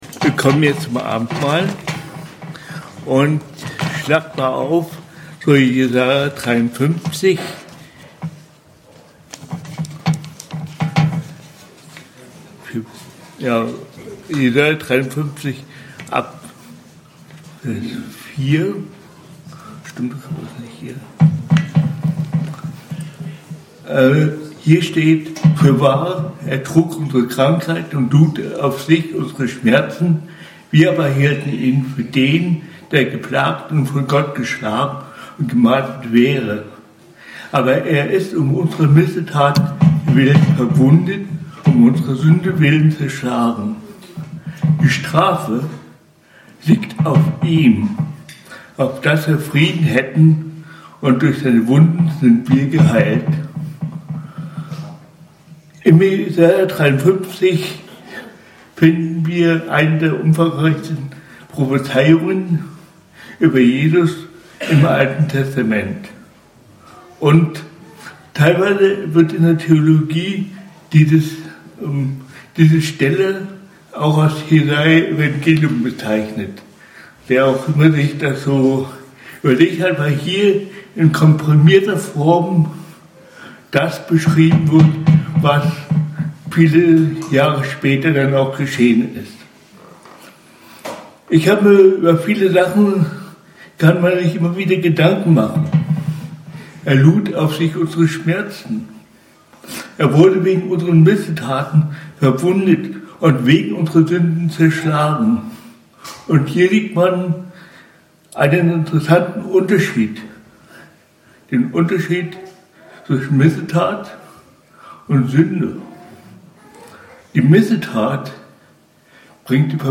Frieden durch Einheit von Jesus' Geburt und Kreuz - Abendmahl ~ BGC Predigten Gottesdienst Podcast